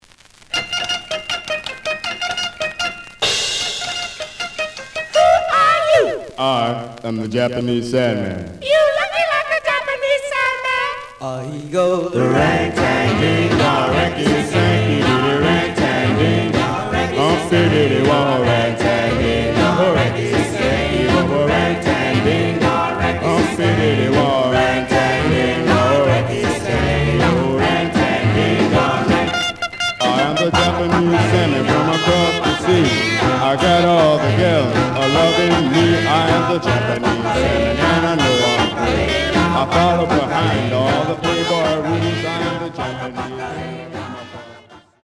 試聴は実際のレコードから録音しています。
●Genre: Rhythm And Blues / Rock 'n' Roll
●Record Grading: G+ (両面のラベルにダメージ。A面のラベルに書き込み。盤に歪み。プレイOK。)